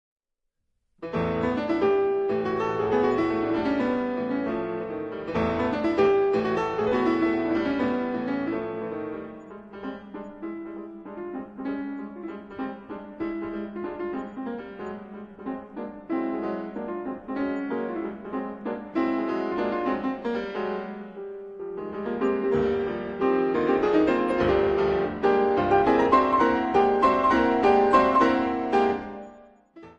fortepian / piano